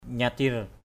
/ɲa-tir/ (t.) sáng lòa, rực rỡ. hadah nyatir hdH Vt{R tỏa hào quang.
nyatir.mp3